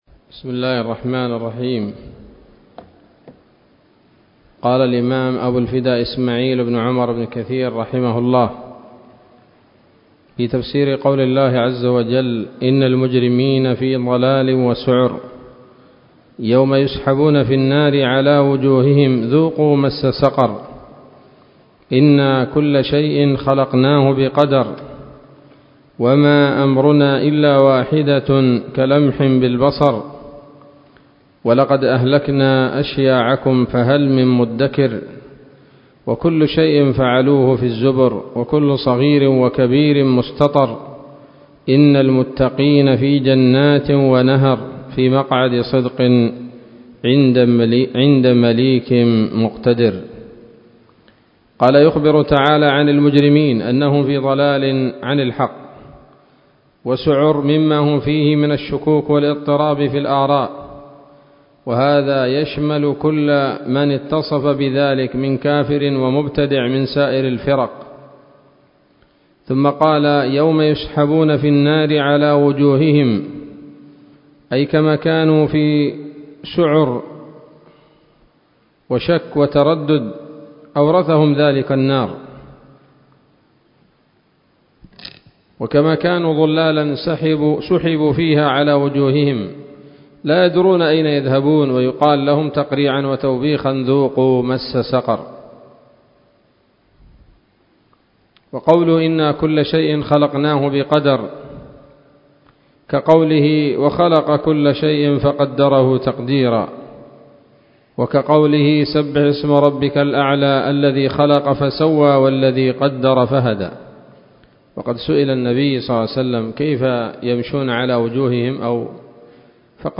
الدرس الخامس من سورة القمر من تفسير ابن كثير رحمه الله تعالى